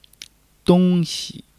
dong1-xi.mp3